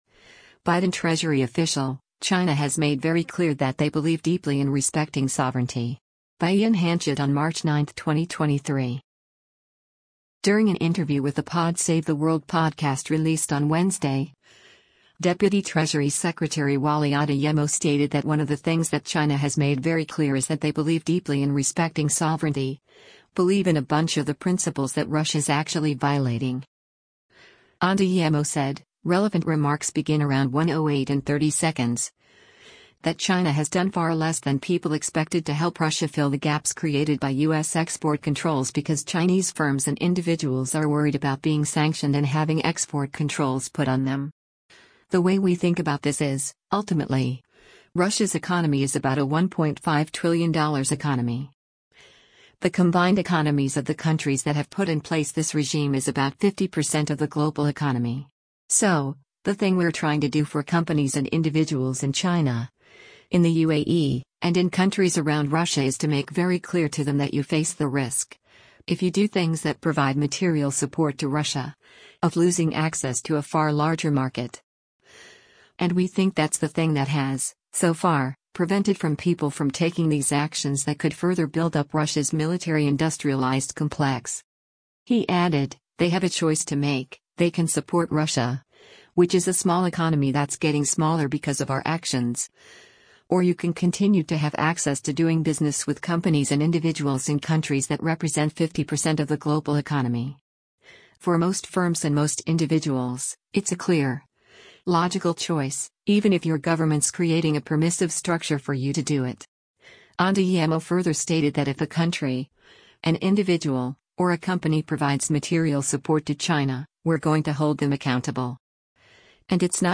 During an interview with the “Pod Save the World” podcast released on Wednesday, Deputy Treasury Secretary Wally Adeyemo stated that “one of the things that China has made very clear is that they believe deeply in respecting sovereignty, believe in a bunch of the principles that Russia’s actually violating.”